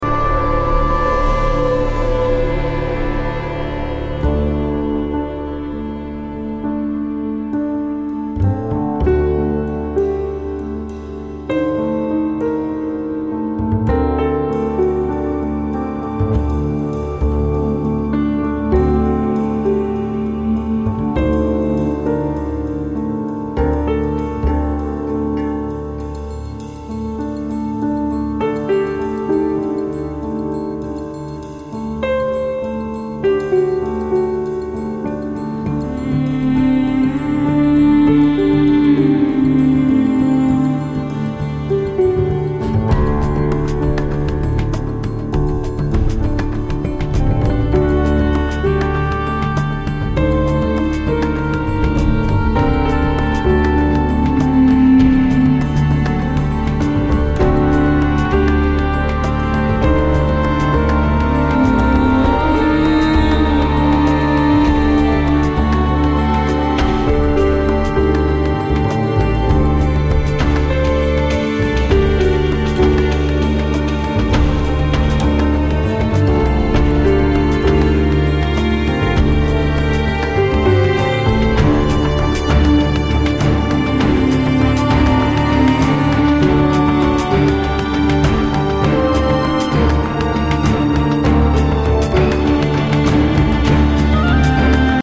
The mysterious and bone-chilling score